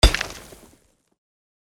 Mining_3.mp3